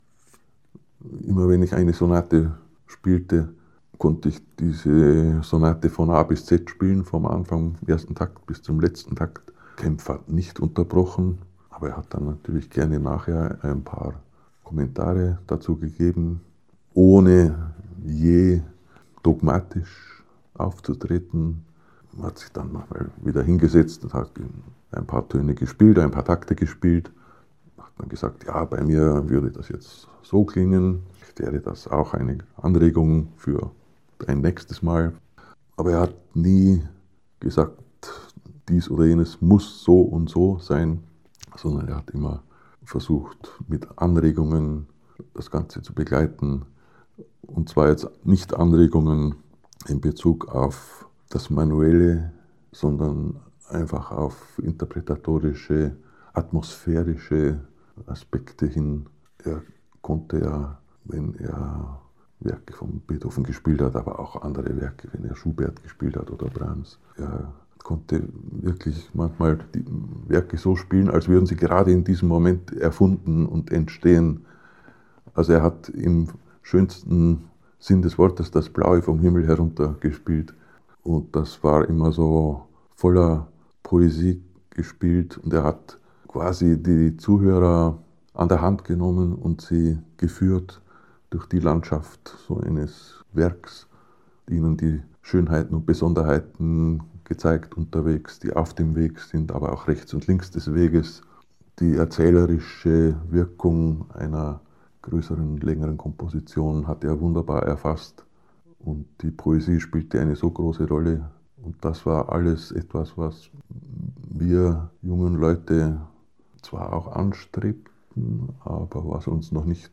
In eight chapters, he reports on the masterclasses at Casa Orfeo and his encounters with Wilhelm Kempff.